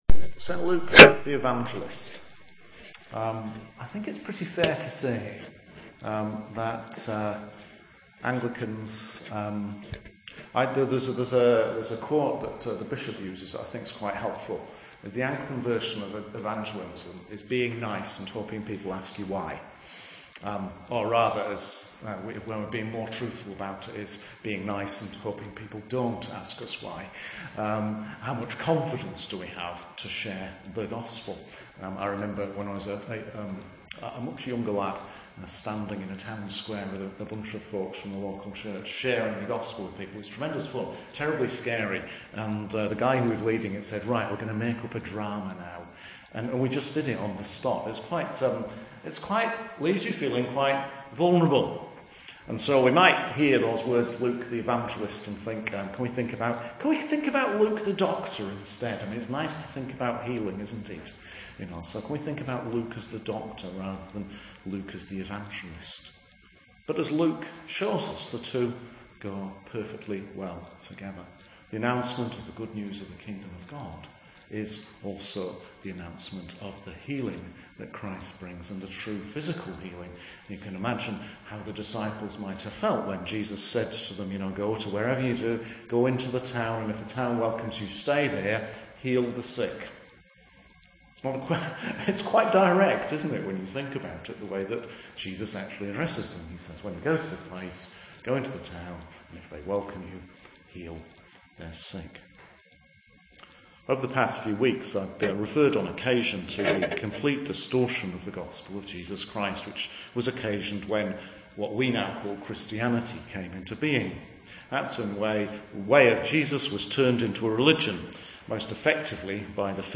Sermon for Luke the Evangelist 2 Timothy 4:5-17 Luke 10:1-16